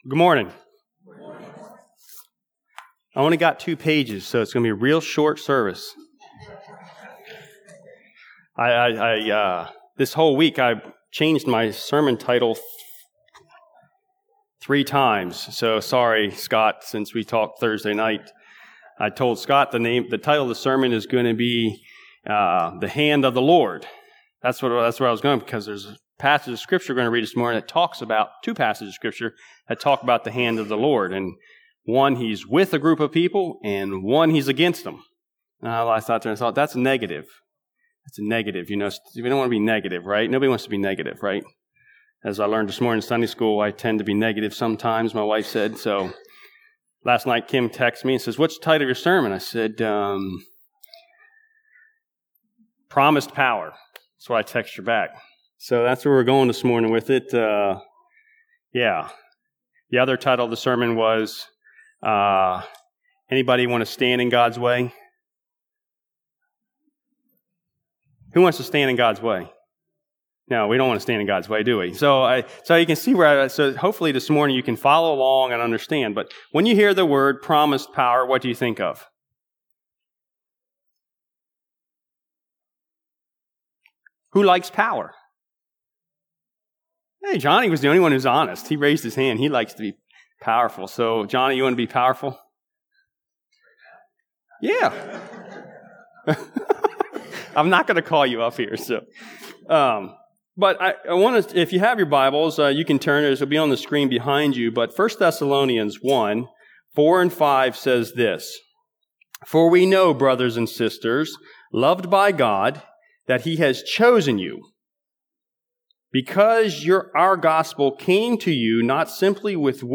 Maranatha Fellowship's Sunday Morning sermon recordings.
Sunday Sermons